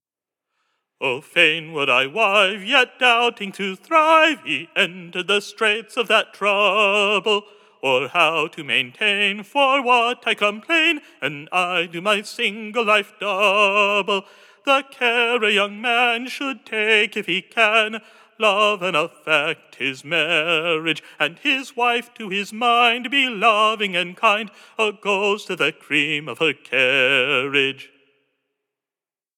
Stanza 1, sung with no leap in with no leap in notes "on 'mar-riage,' also cited in book as “Handprint Ballad